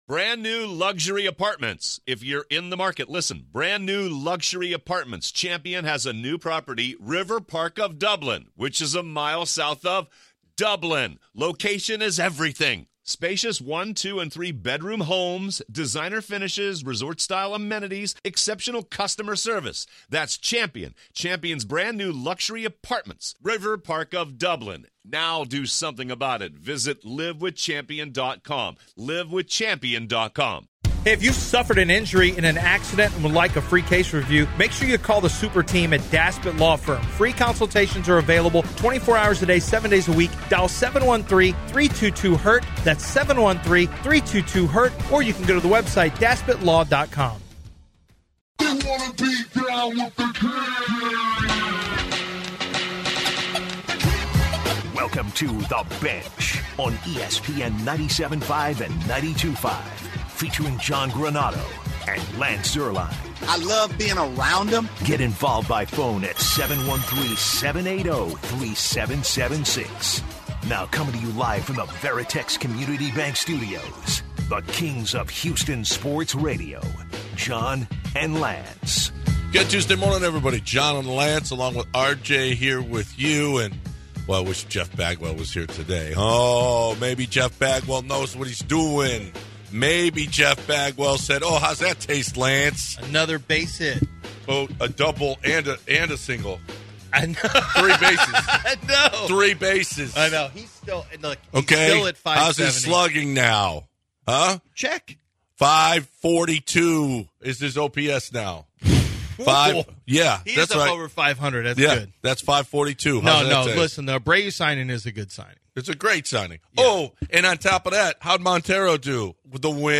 In our opening Hour, we recap the Astros win against the Chicago Cubs and Jose Abreu's solid outing. In addition, the rumors continue to swirl that James Harden will potentially be coming back to the Rockets, but do you want him back? We take calls and vote should the beard come back and cook in Houston...